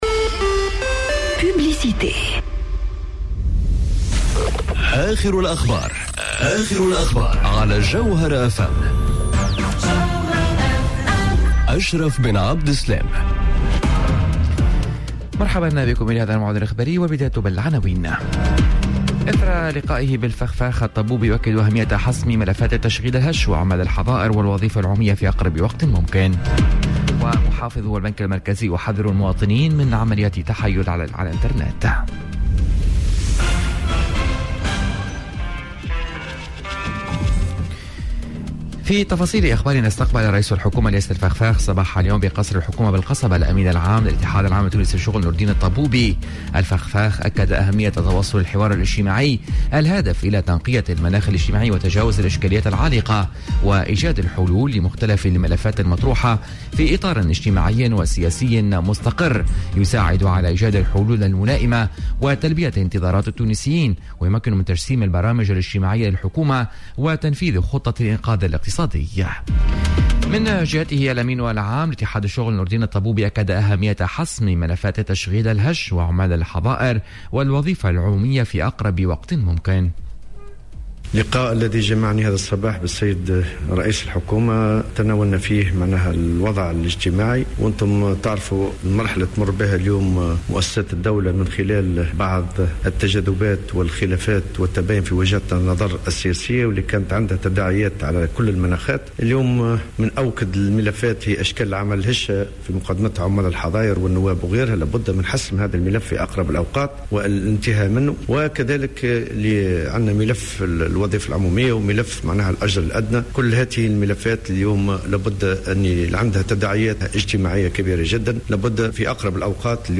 نشرة أخبار منتصف النهار ليوم الإربعاء 01 جويلية 2020